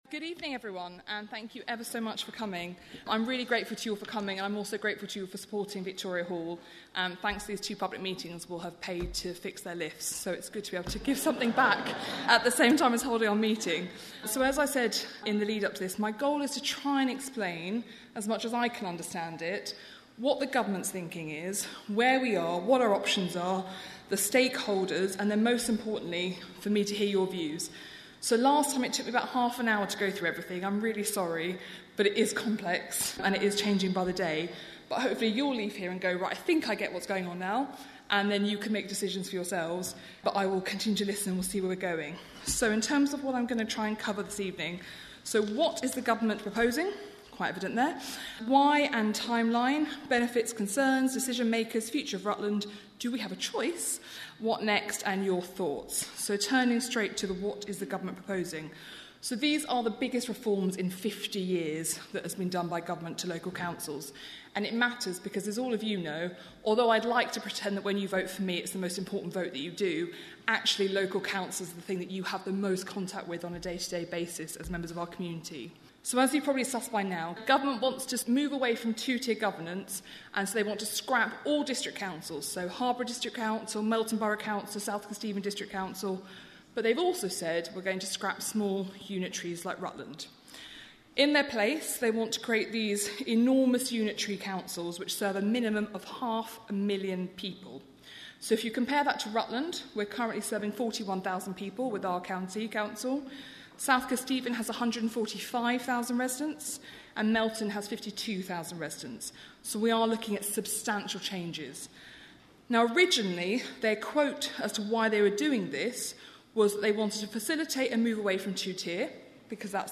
The second meeting at Victoria Hall in Oakham, held by Rutland and Stamford's MP Alicia Kearns explains the government's plans and the possible options, as Rutland is urged to merge with its neighbours in local government reorganisation. Includes questions and views from locals who attended.